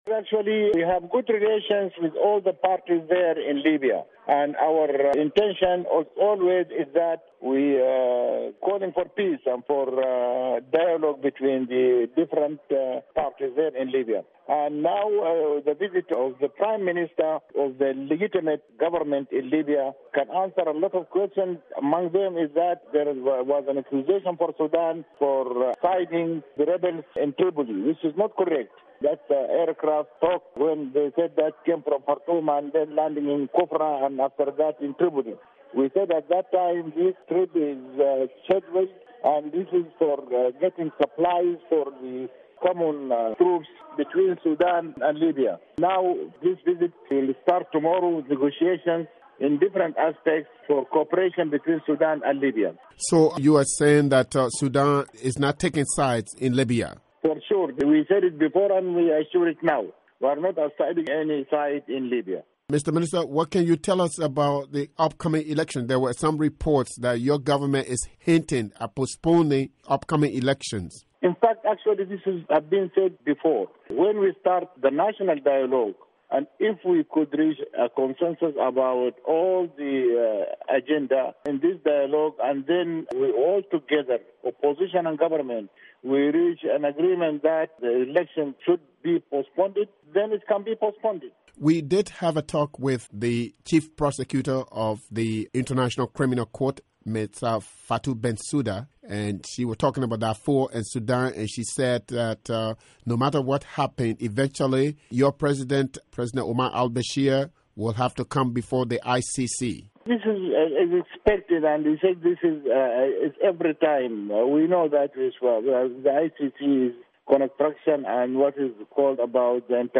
interview with Ahmed Bilal